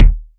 KICK.116.NEPT.wav